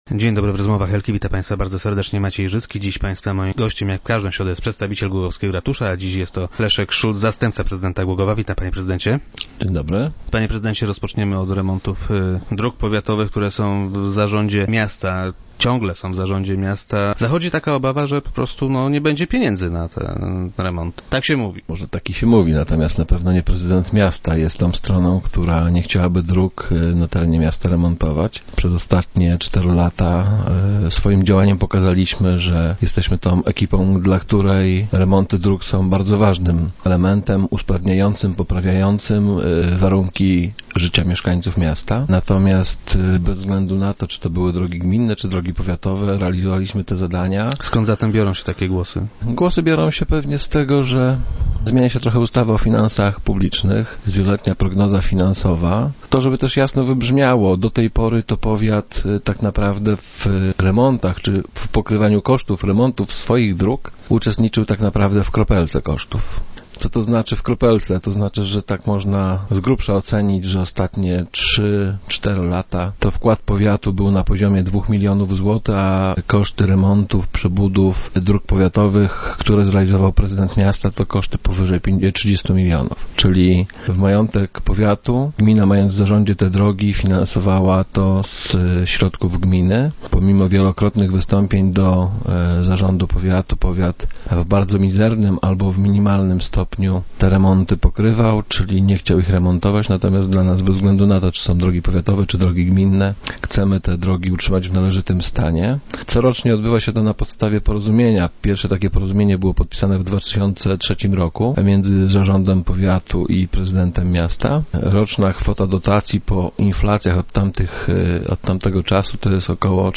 - Utrzymanie, naprawa i inwestycje na powiatowych drogach za 400 tysięcy jest niemożliwe. W związku z tym, po analizie stanu technicznego dróg powiatowych i szacowanych kosztów ich utrzymania, przygotowaliśmy nowe porozumienie dla powiatu. W ramach tego porozumienia to w przyszłym roku powiat powinien przekazać gminie co najmniej 4,5 miliona złotych - powiedział wiceprezydent Szulc, który był dziś gościem Rozmów Elki.